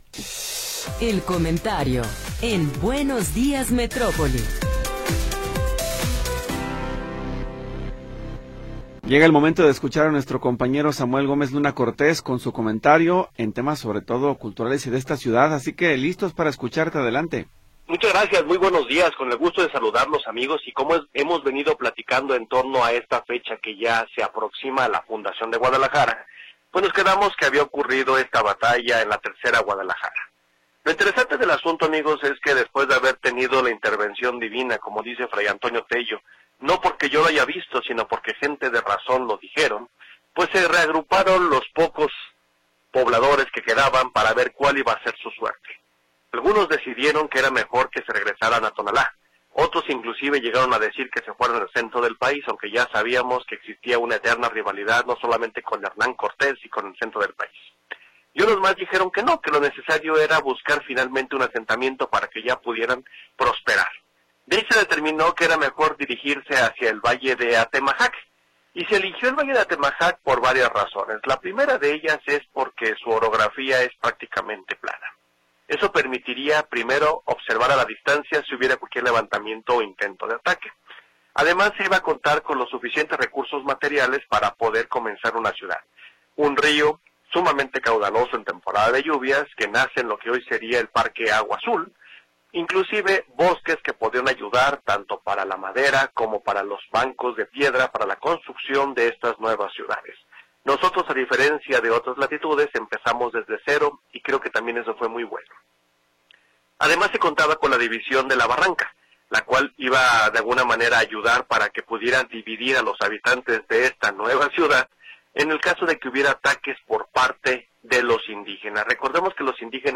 Comentario de Samuel Gómez Luna Cortés